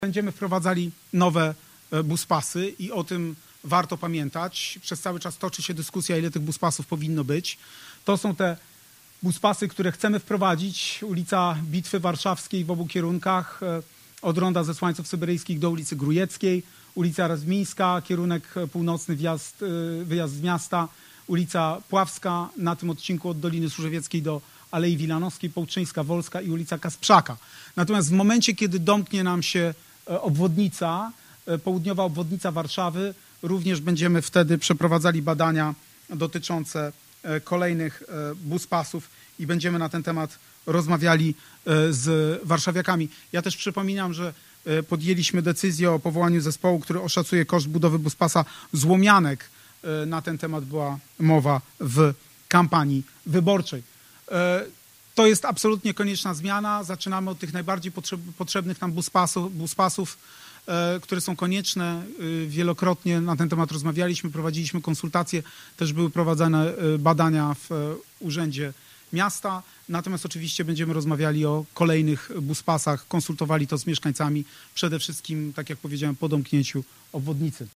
Cała wypowiedź Rafała Trzaskowskiego: